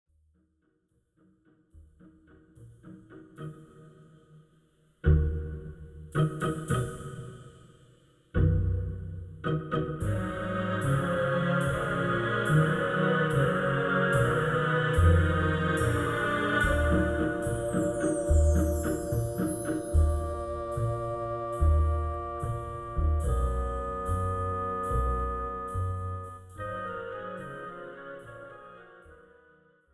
Without Backing Vocals. Professional Karaoke Backing Track.
This is an instrumental backing track cover.
Key – E♭m, E♭, Fm, F
No Fade